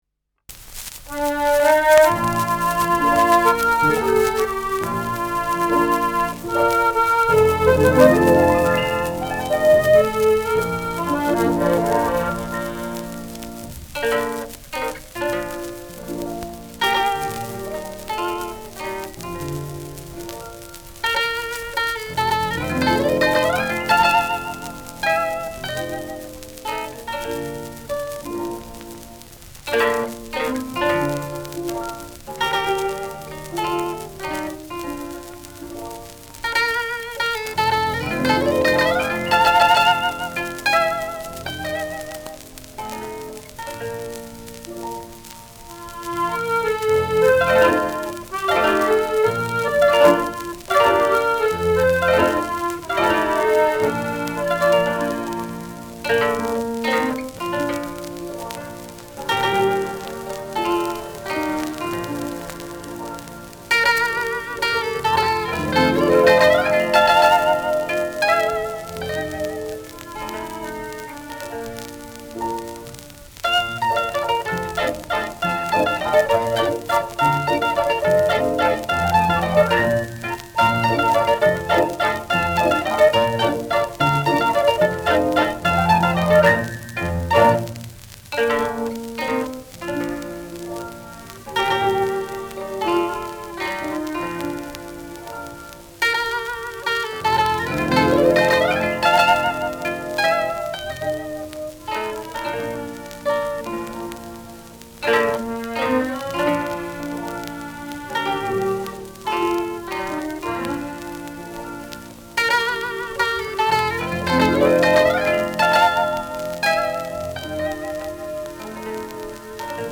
Schellackplatte
Tonrille: einige graue Rillen
präsentes Knistern : leichtes Rauschen : leichtes Leiern
[unbekanntes Ensemble] (Interpretation)
[Berlin] (Aufnahmeort)